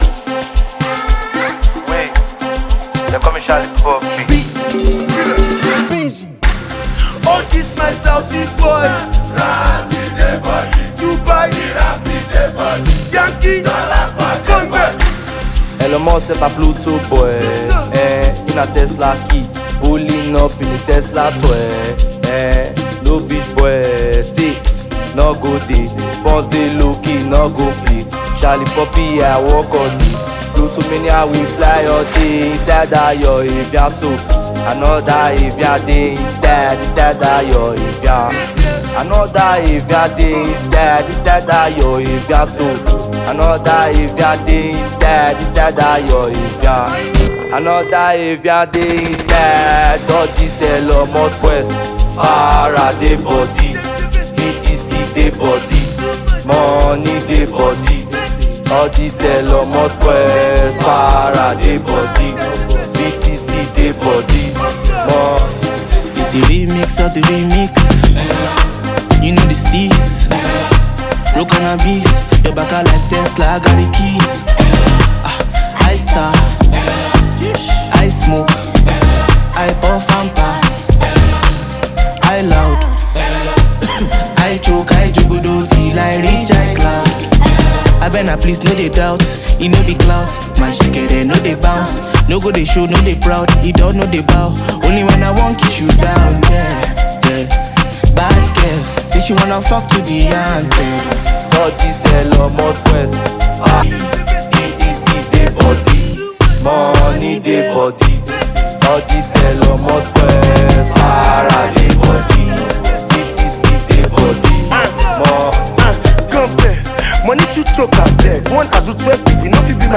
groovy record
Amapiano record
Afro vibes